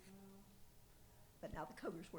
Weston (W. Va.)